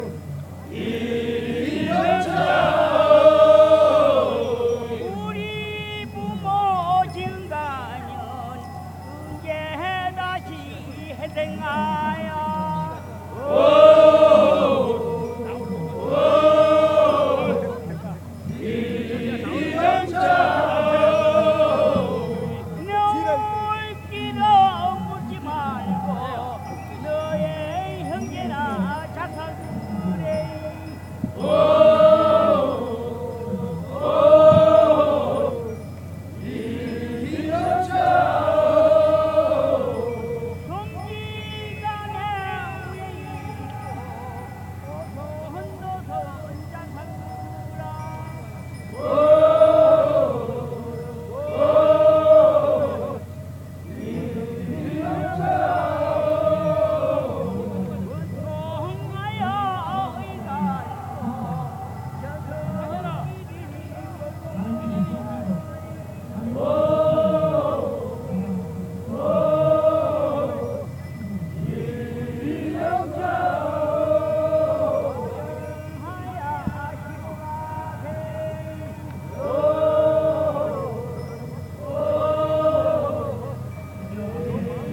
상여.mp3